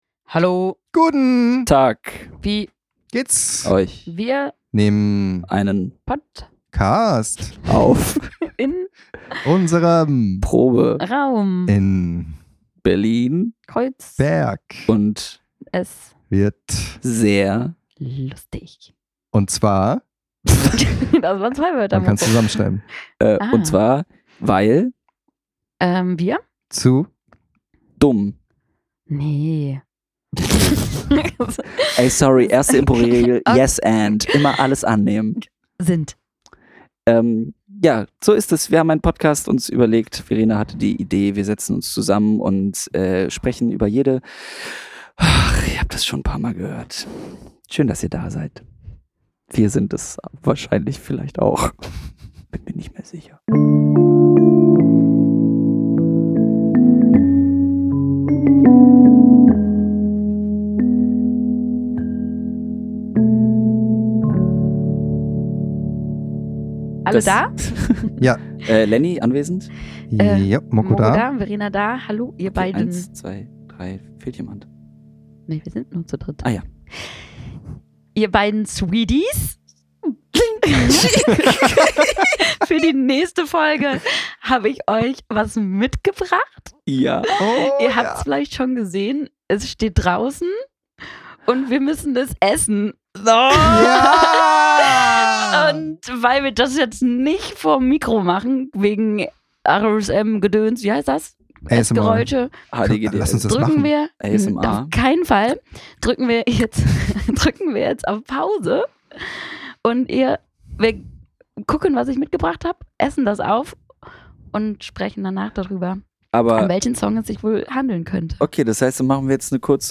Anstatt euch unnötig vollzuschmatzen, spielen wir unnötige Musik und nennen sie Jazz.